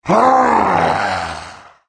naga_warrior_die.wav